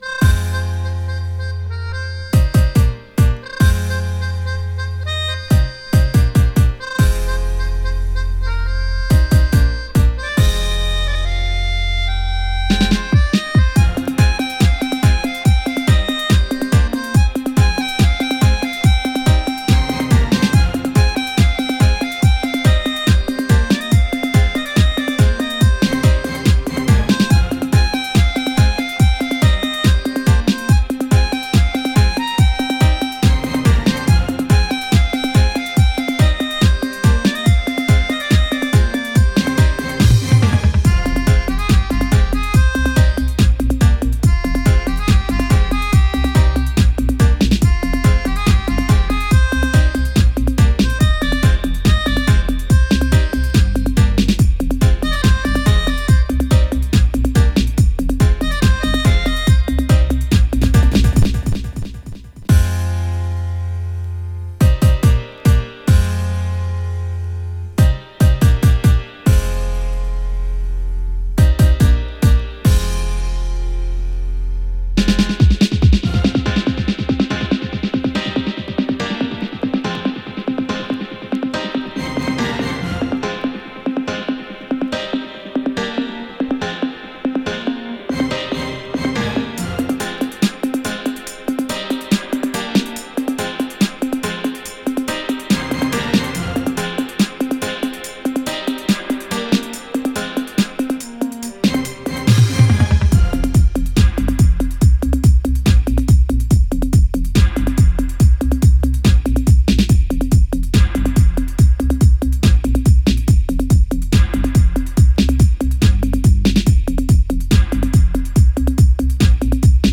Dubwise